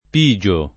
vai all'elenco alfabetico delle voci ingrandisci il carattere 100% rimpicciolisci il carattere stampa invia tramite posta elettronica codividi su Facebook pigiare v.; pigio [ p &J o ], pigi — fut. pigerò [ pi J er 0+ ]